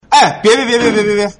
sound-wash.mp3